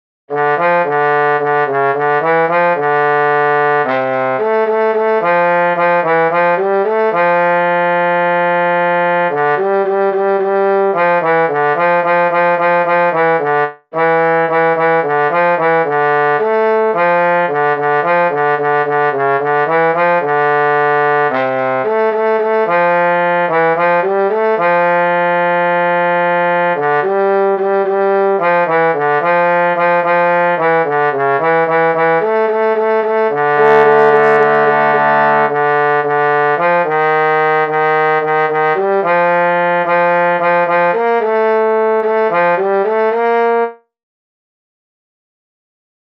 Jewish Folk Song (popular in Chabad-Lubavitch after davening)
D minor ♩= 110 bpm